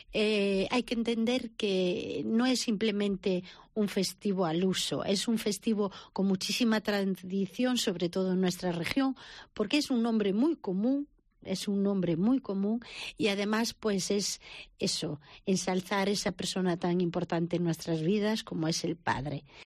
en una entrevista en Cope